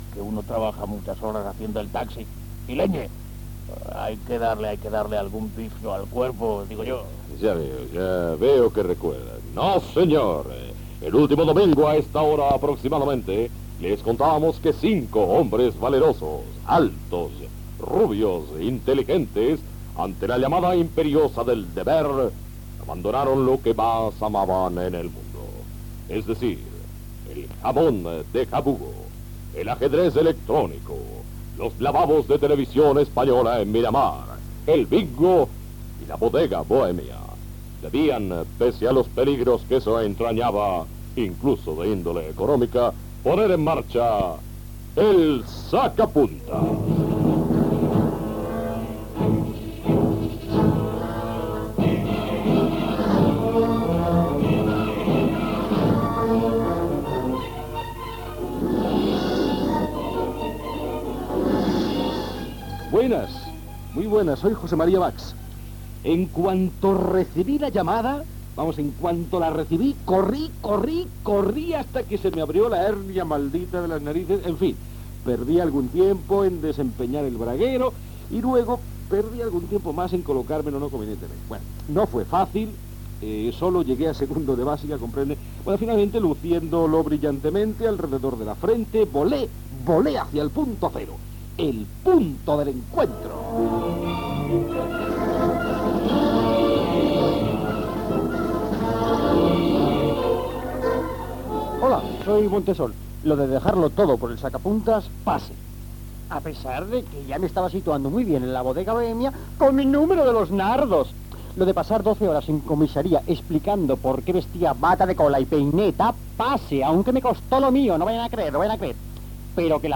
Banda FM